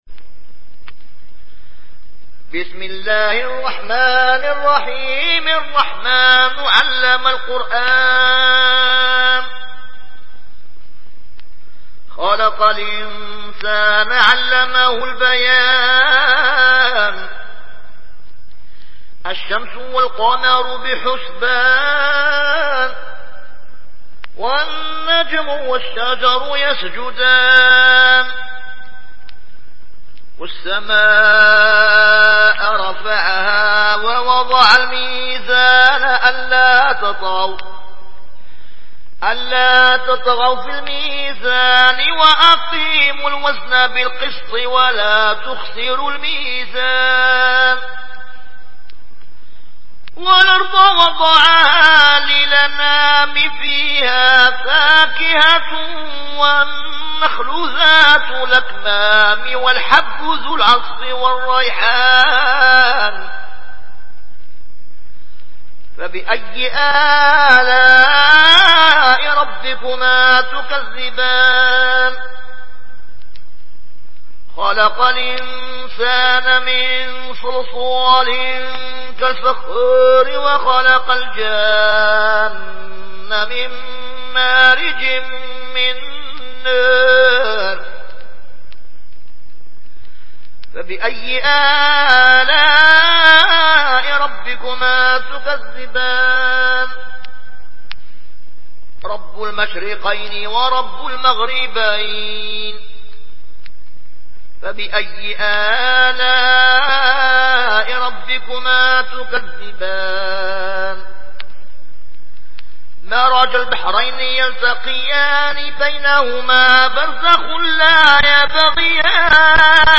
Warsh থেকে Nafi